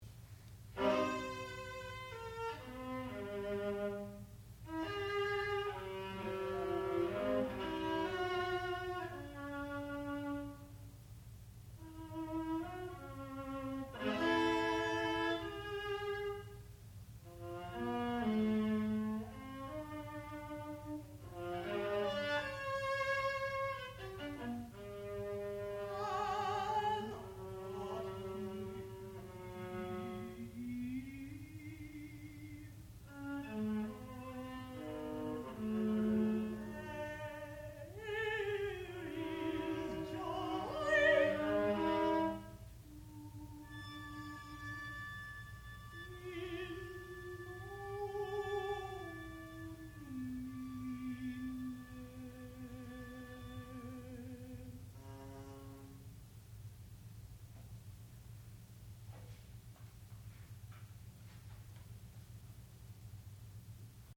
sound recording-musical
classical music
mezzo-soprano
viola